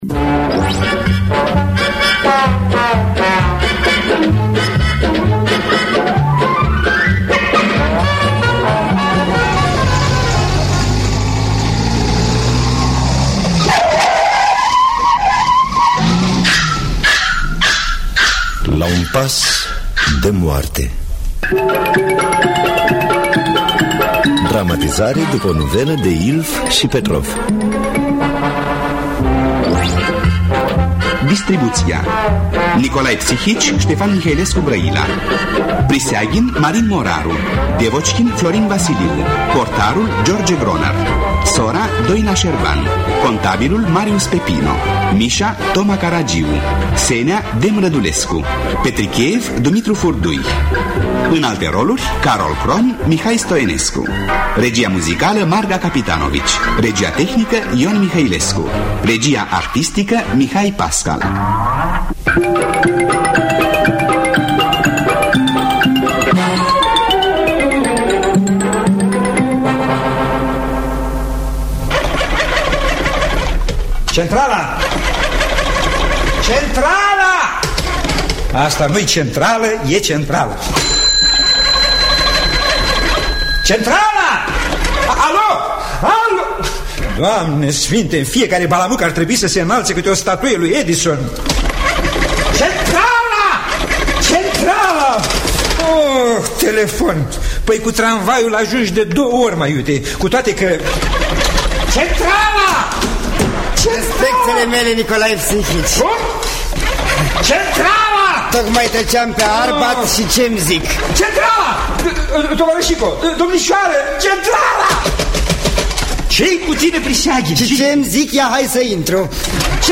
Dramatizare radiofonică